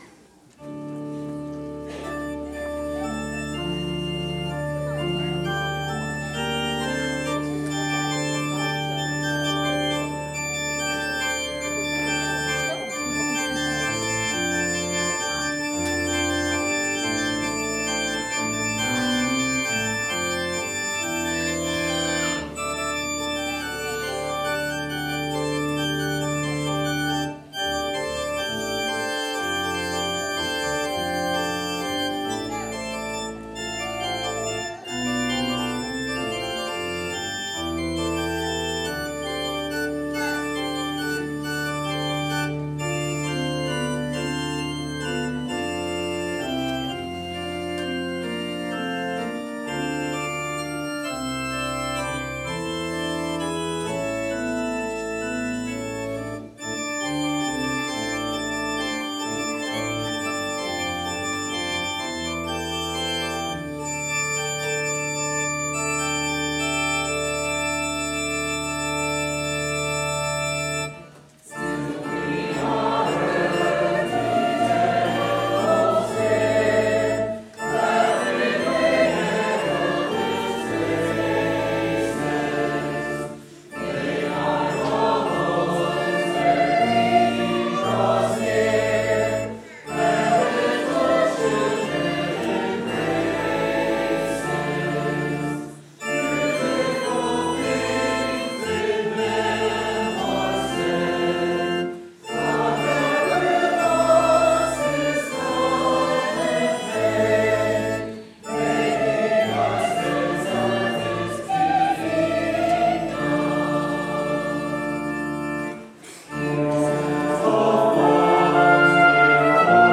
The opening hymn, Psalmody, sermon hymn, and closing hymn include congregation singing.
Closing Hymn Stanzas 5-7